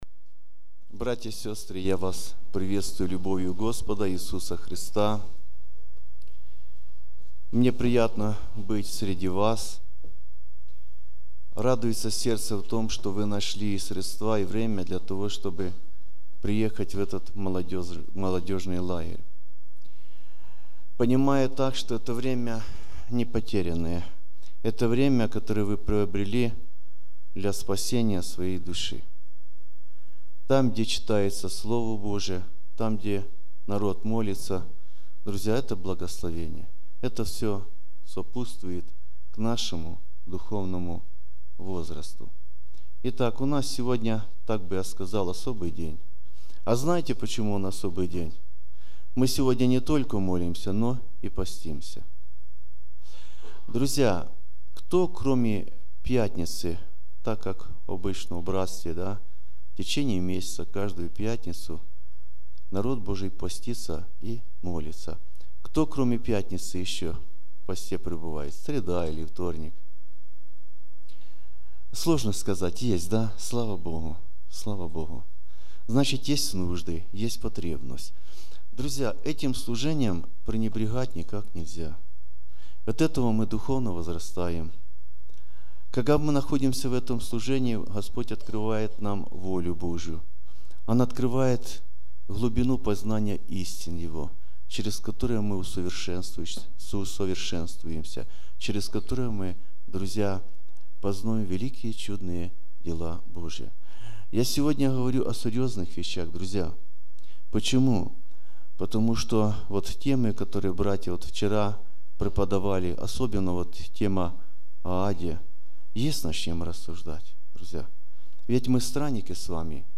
01 Prayer Hour.mp3